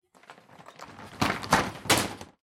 На этой странице собраны звуки традиционных японских ширм – редкие и атмосферные аудиозаписи.
Сложили ширму для экономии места